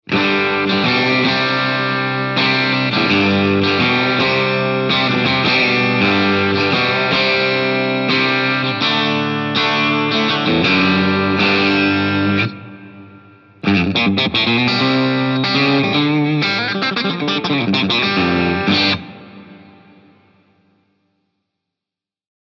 HD电吉他
备注：这个型号的颈部拾音器位置，像原版一样，声音非常“低沉”，绕过音色控制。